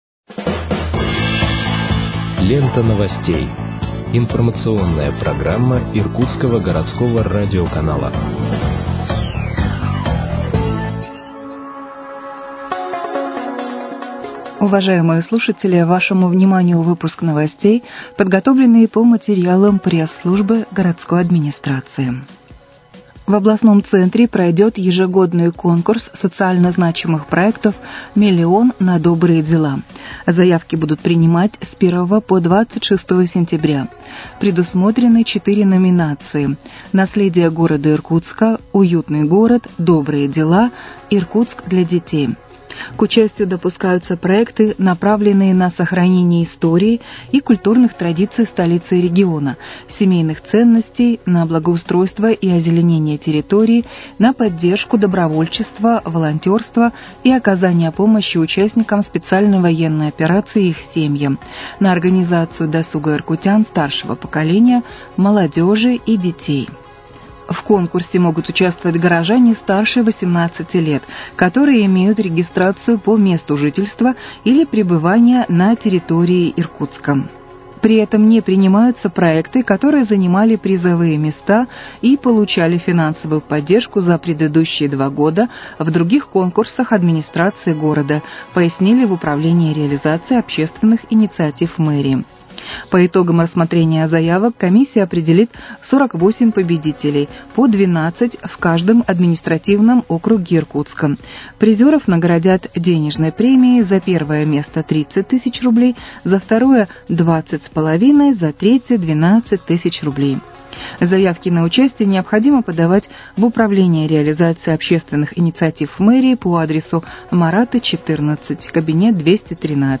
Выпуск новостей в подкастах газеты «Иркутск» от 27.08.2025 № 1